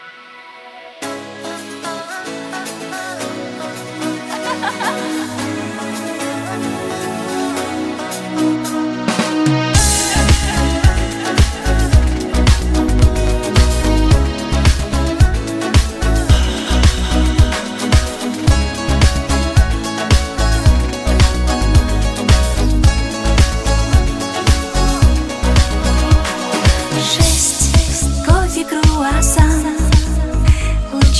• Качество: 170, Stereo
поп
громкие
инструментальные